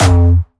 Jumpstyle Kick 4